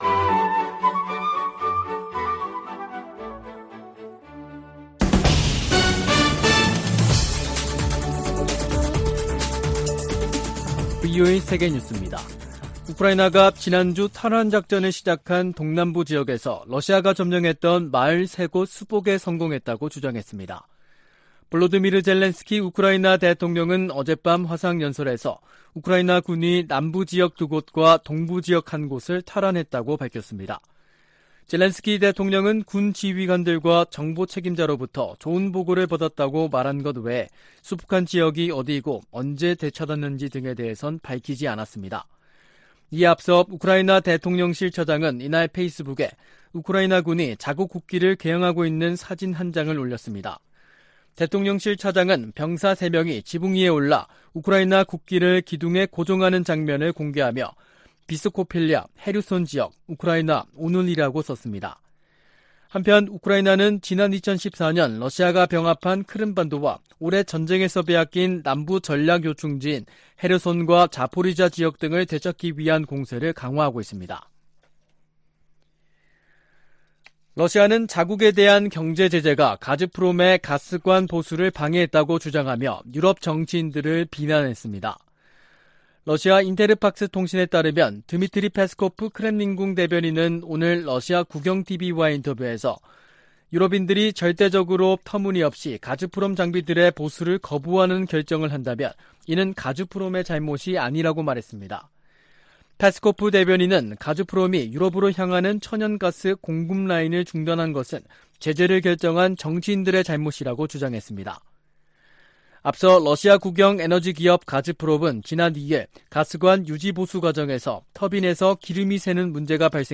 VOA 한국어 간판 뉴스 프로그램 '뉴스 투데이', 2022년 9월 5일 2부 방송입니다. 미국과 한국, 일본 북핵 수석대표가 일본에서 회동하고 북한 비핵화 문제 등을 논의합니다.